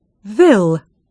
Hur uttalas ordet stad ? [stɑːd]